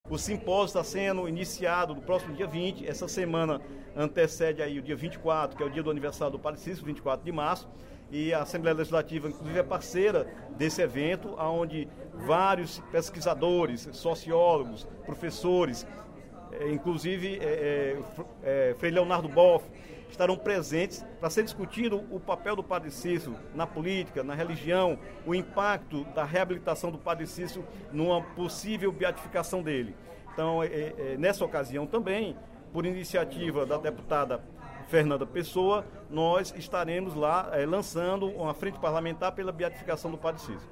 O deputado Dr. Santana (PT) solicitou a realização de plebiscito sobre a mudança do nome do município de Juazeiro do Norte para Juazeiro do Padre Cícero. O pronunciamento ocorreu durante o primeiro expediente da sessão plenária desta quinta-feira (16/03).